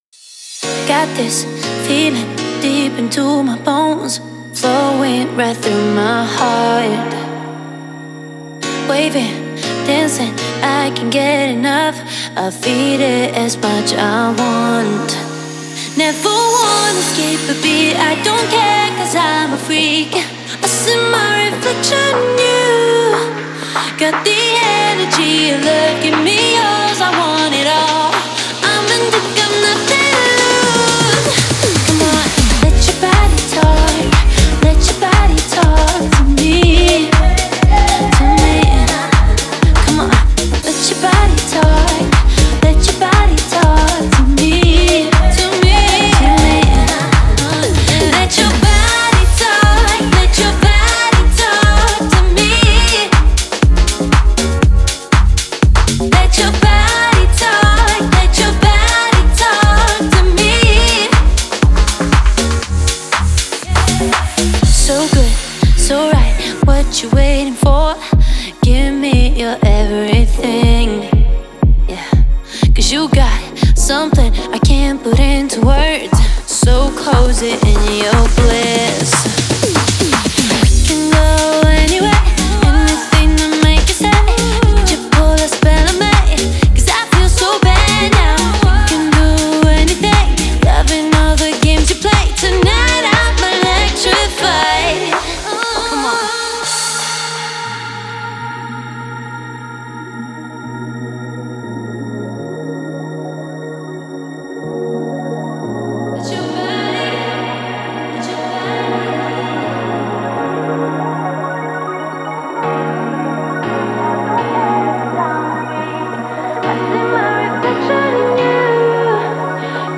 динамичная поп- и EDM-композиция